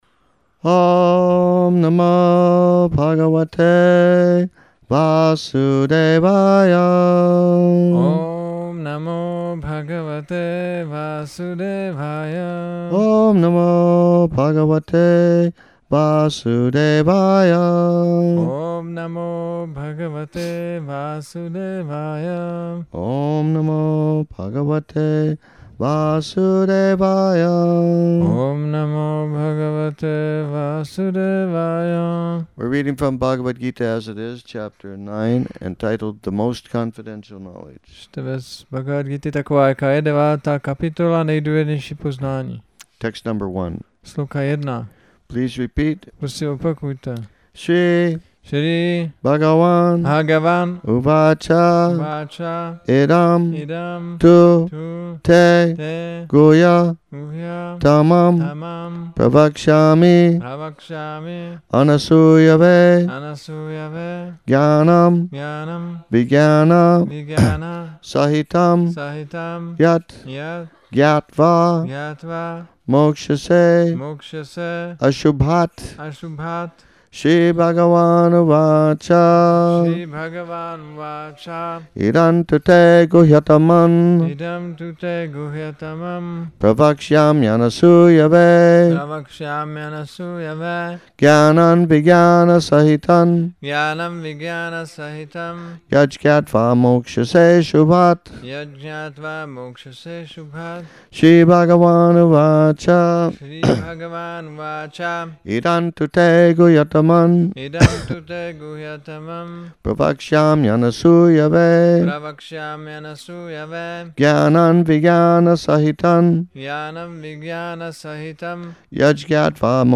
Přednáška BG-9.1 – Šrí Šrí Nitái Navadvípačandra mandir